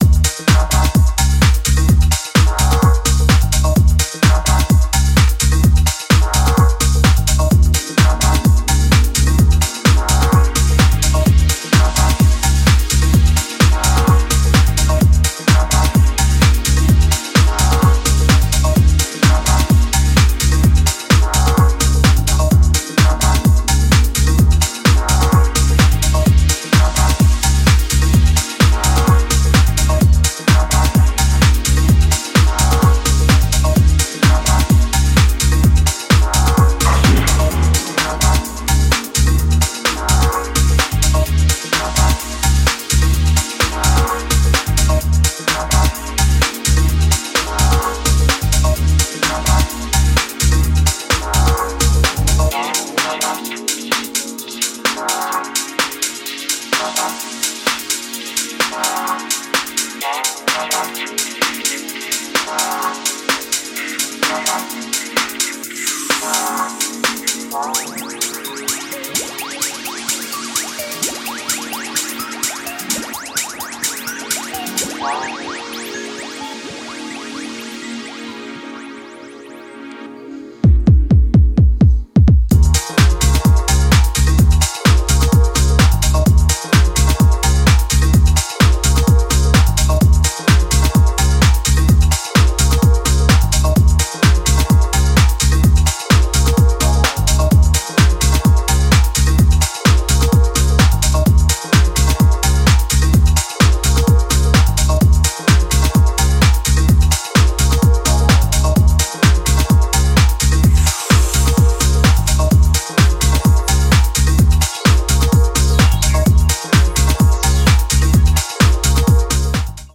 A useful, heartwarming EP of modern deep house music.